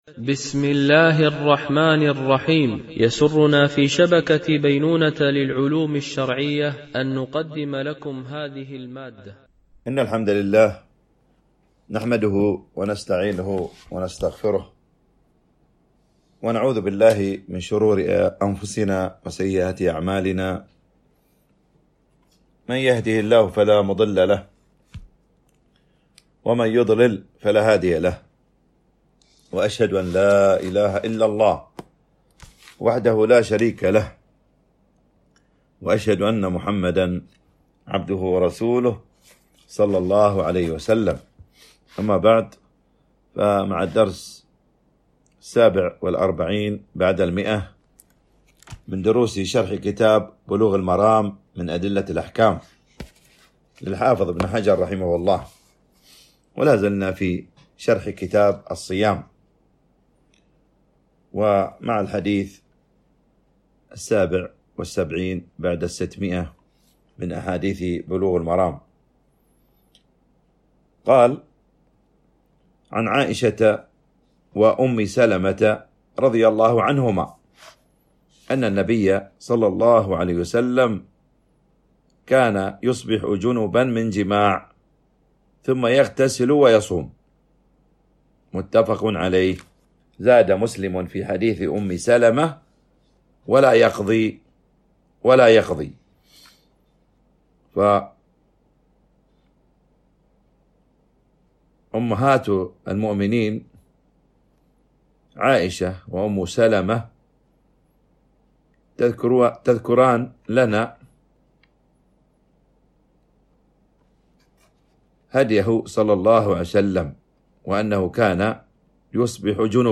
التنسيق: MP3 Mono 44kHz 64Kbps (CBR)